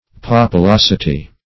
Search Result for " populosity" : The Collaborative International Dictionary of English v.0.48: Populosity \Pop`u*los"i*ty\, n. [L. populositas: cf. F. populosit['e].]